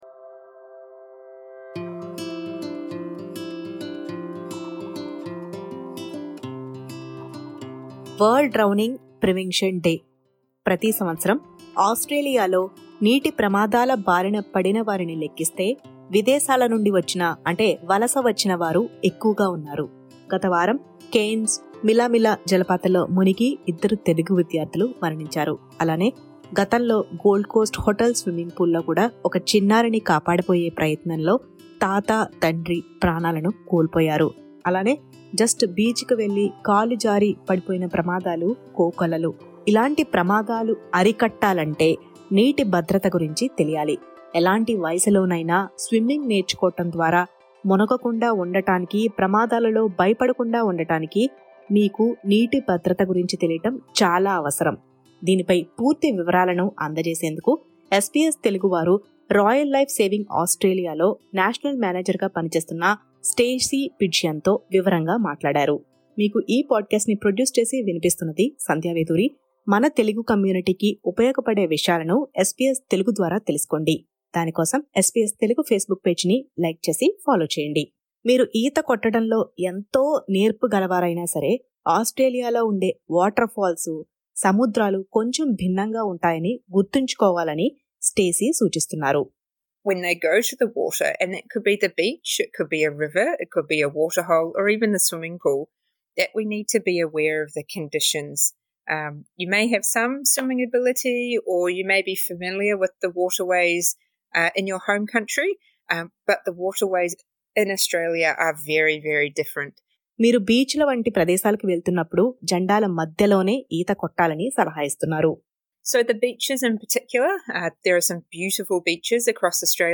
ఇంటర్వ్యూ చేసారు